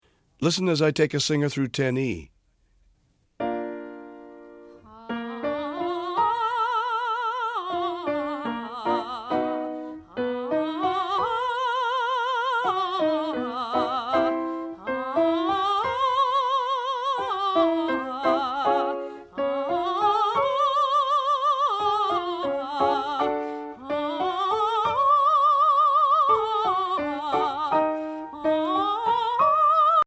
Voicing: Voice/2 CD